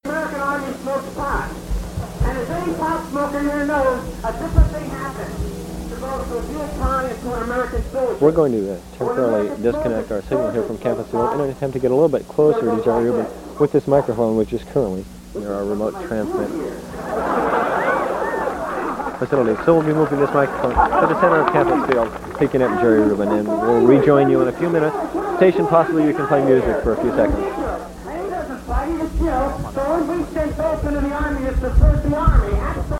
In the background of the audio, a man is heard shouting “right on” in accordance with the enthusiastic crowd’s cheers.
When Rubin began to speak, the staffers moved closer to the scene so that the sound would be less shaky.